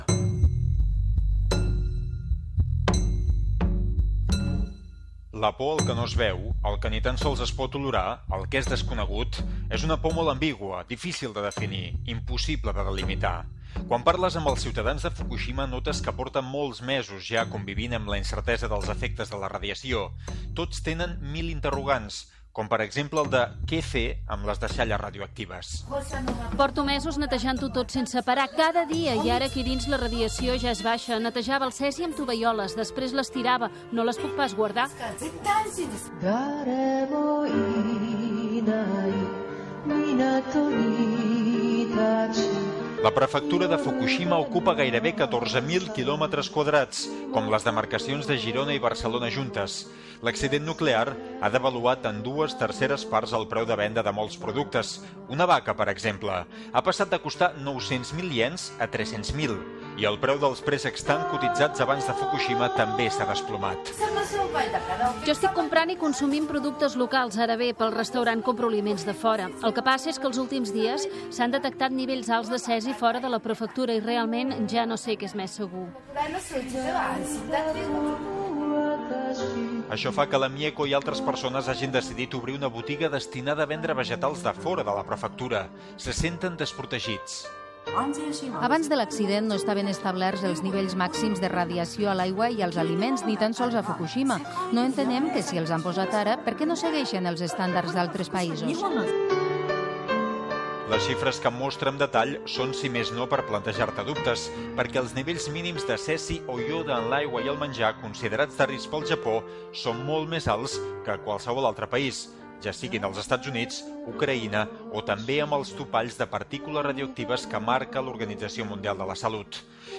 Reportatge fet vuit mesos després del terratrèmol i tsunami a Fukushima (Japó) on hi ha una central nuclear que va resultar afectada i que s'haurà de desmantellar dècades després
Informatiu